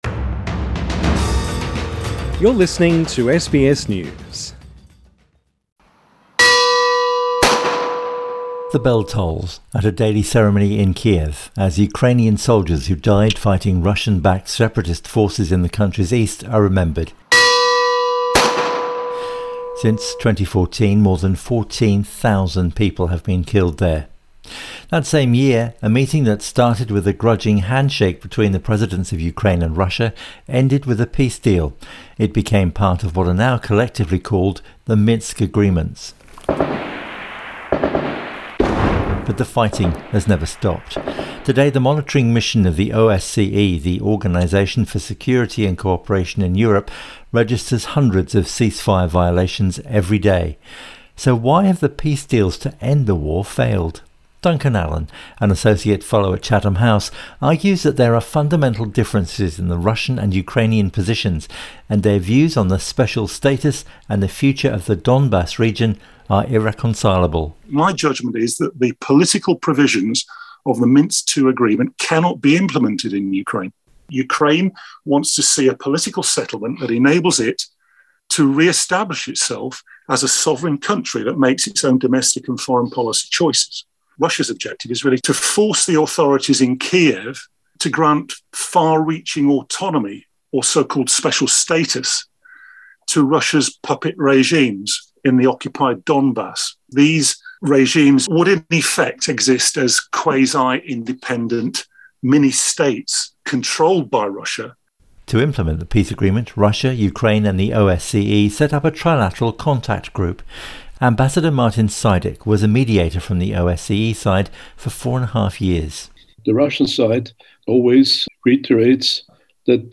This item was first broadcast on the BBC World Service